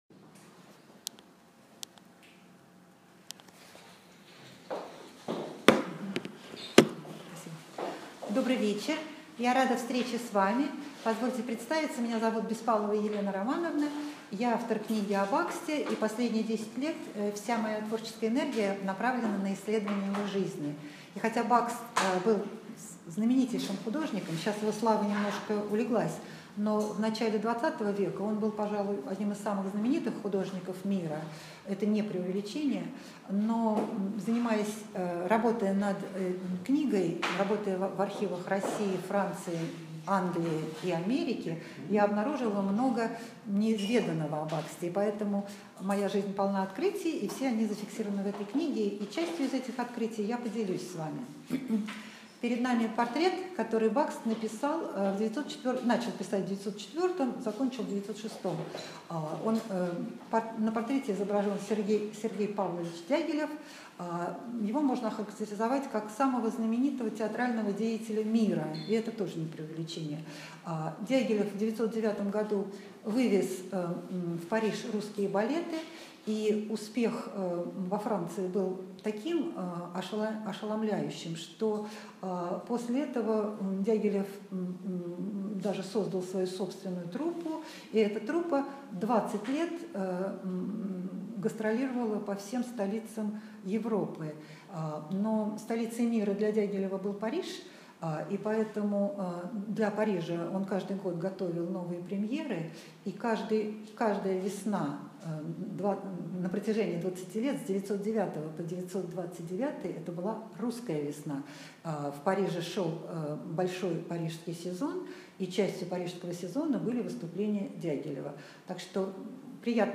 Аудиокнига Ида Рубинштейн – муза и заказчица художника Бакста | Библиотека аудиокниг